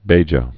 (bājə)